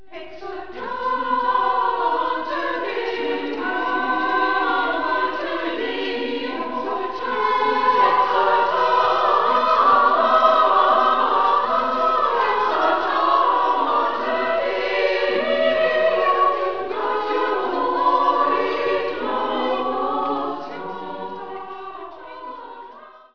Zur Zeit singen ca. 35 Frauen aktiv mit.
so klingt der Frauenchor